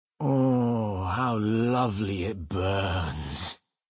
Media:Schirrú voice line.ogg